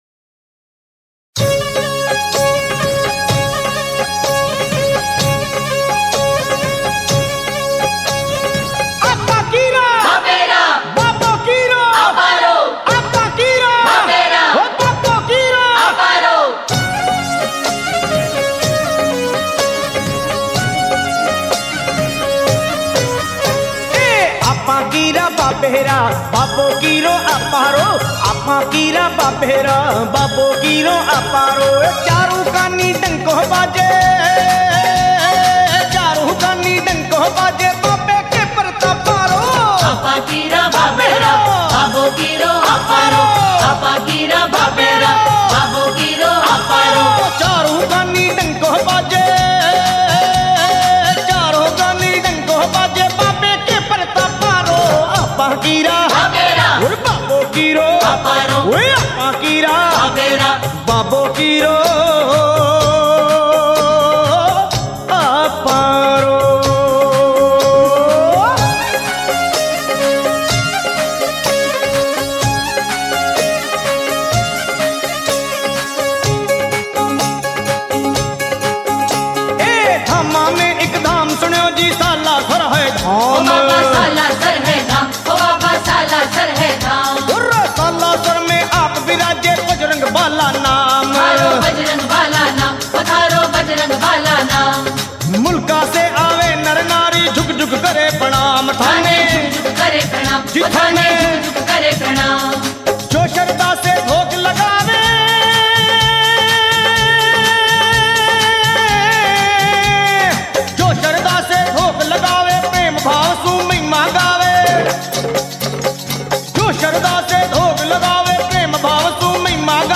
Rajasthani Songs
Balaji Bhajan